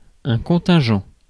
Ääntäminen
IPA: /kɔ̃.tɛ̃.ʒɑ̃/